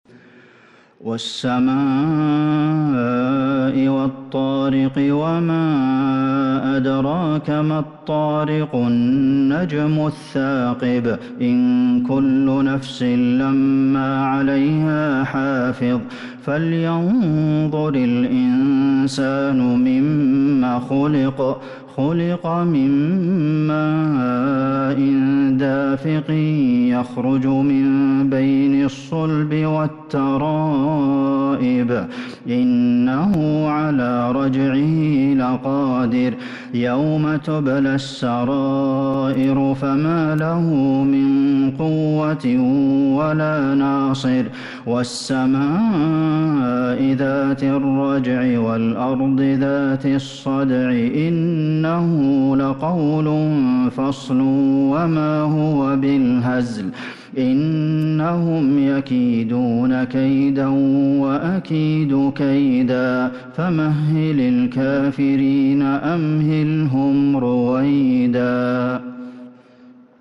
سورة الطارق Surat At-Tariq من تراويح المسجد النبوي 1442هـ > مصحف تراويح الحرم النبوي عام ١٤٤٢ > المصحف - تلاوات الحرمين